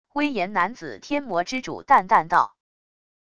威严男子天魔之主淡淡道wav音频